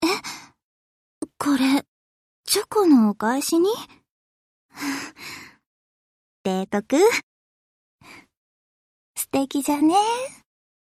She speaks in a Hiroshima dialect.